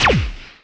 laser2.mp3